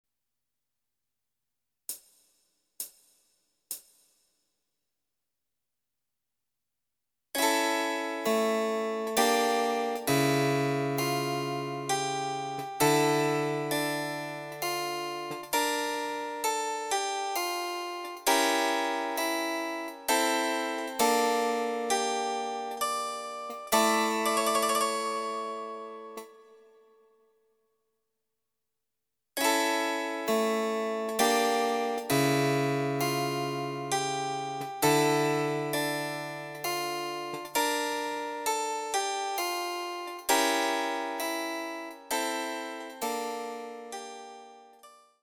試聴ファイル（伴奏）
ソナタ　第１番　ヘ長調
デジタルサンプリング音源使用
※フルート奏者による演奏例は収録されていません。